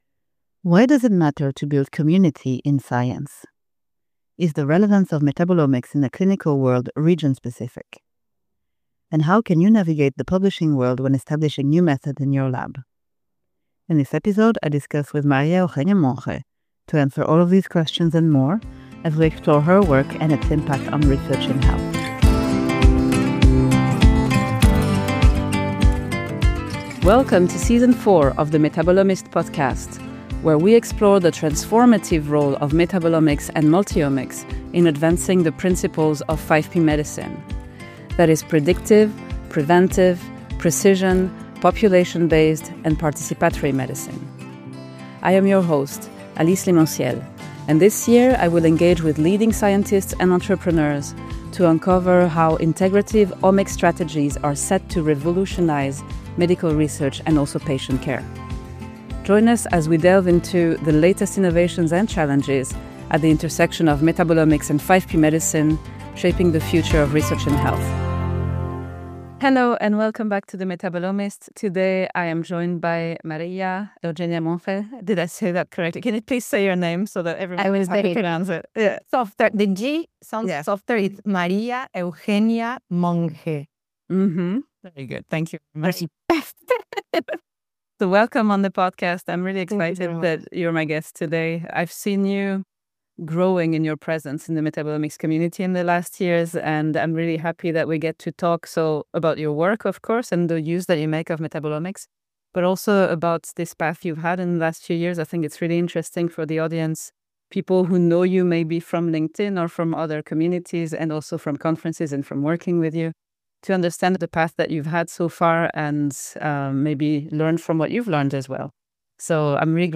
In this interview podcast you can learn how scientists and entrepreneurs create the future of metabolomics. Learn about their struggles, stresses and successes to shape the story of metabolomics as it is today and what they believe about the future of the field.